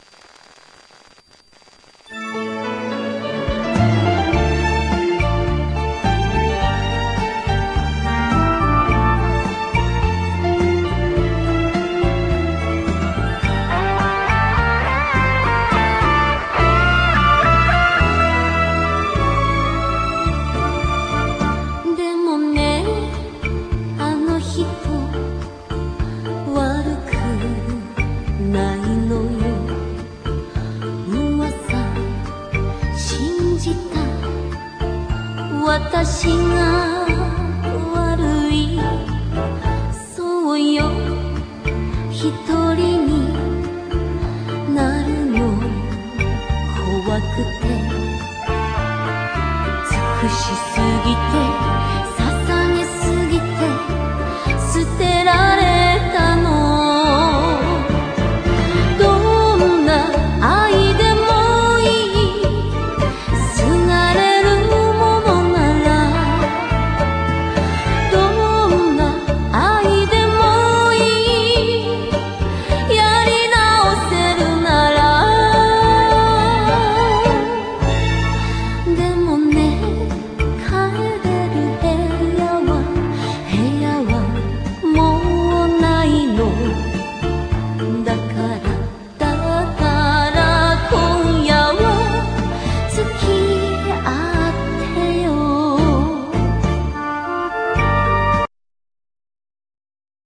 has rumba as a basis